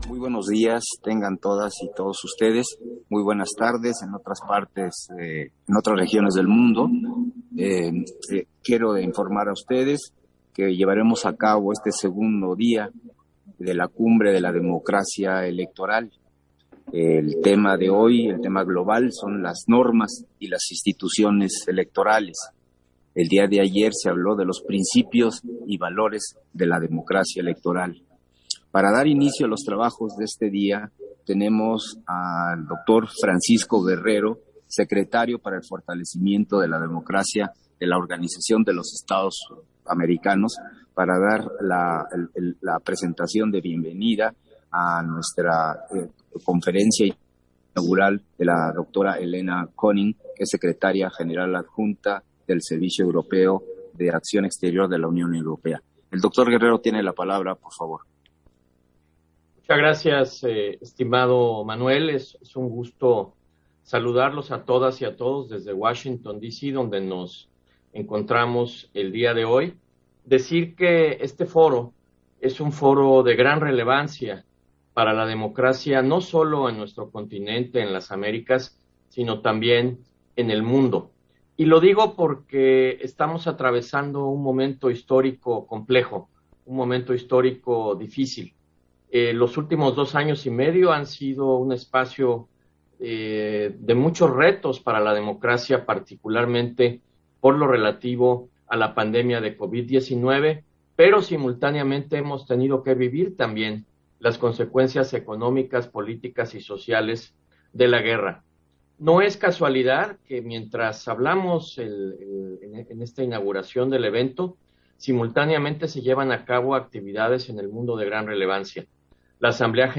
Versión estenográfica de la bienvenida y conferencia inaugural en el segundo día de la Cumbre Global de la Democracia Electoral